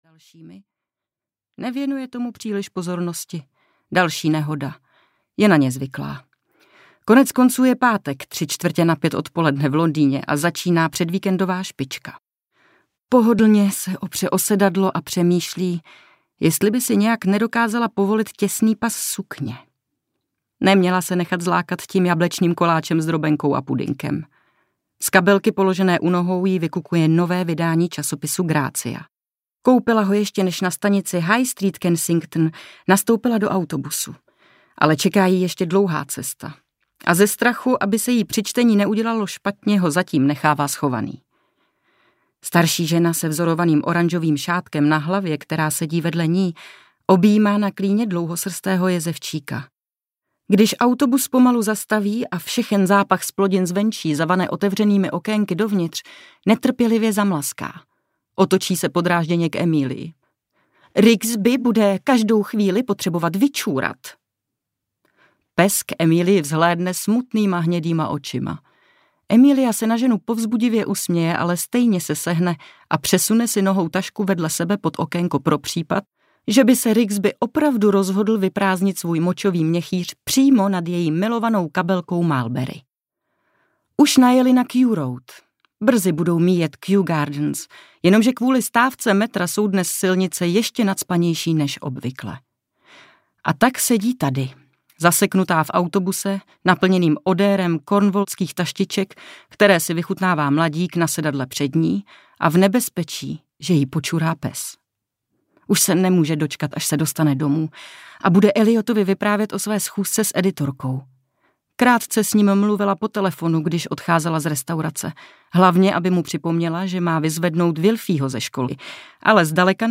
Skrytá za lží audiokniha
Ukázka z knihy